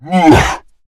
spawners_mobs_balrog_hit.2.ogg